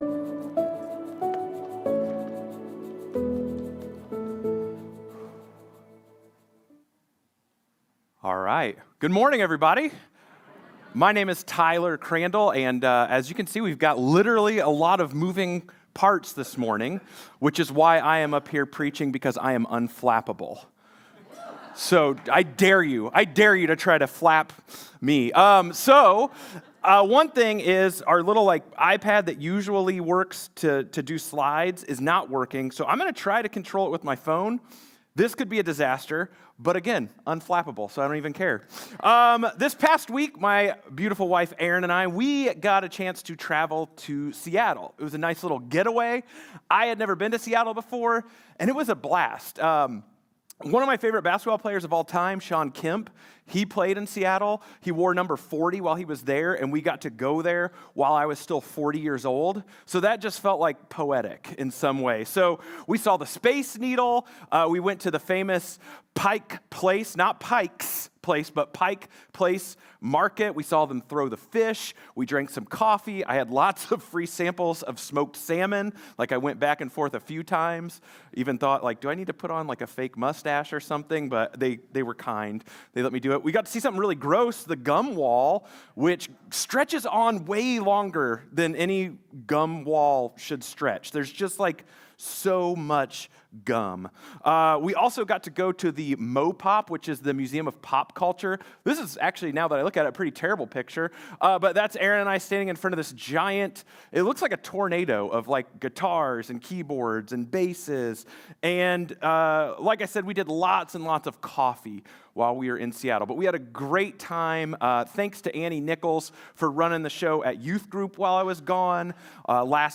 Sermons – Commonway Church